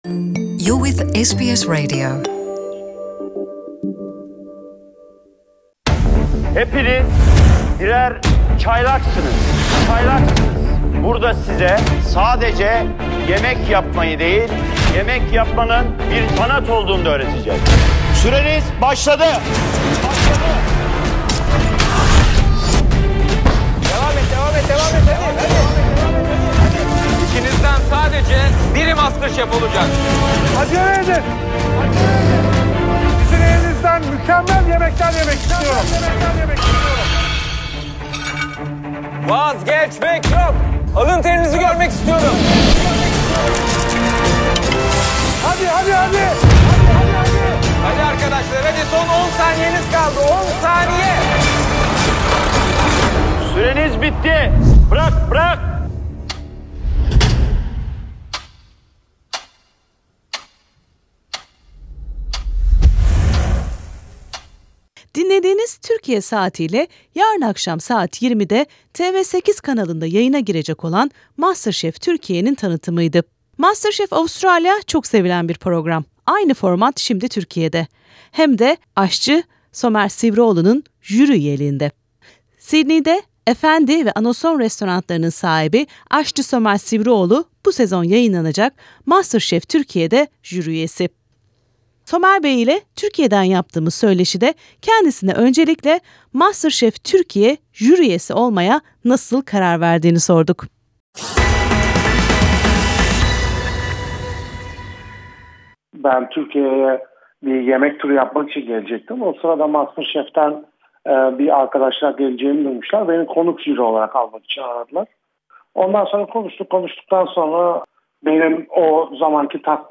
Türkiye'de ilk kez yayınlanacak olan MasterChef Türkiye'de Sydney'li restaurant sahibi ve aşçı Somer Sivrioğlu jüri üyesi olarak görev yapacak. Aşçı Somer Sivrioğlu ile televizyon programı ve Türk mutfağını konuştuk.